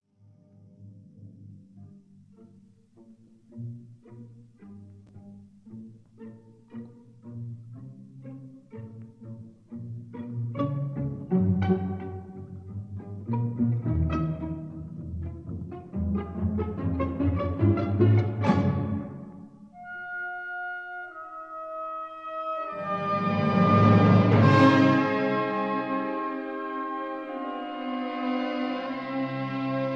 Piu andante - Allegro non troppo ma con brio
stereo recording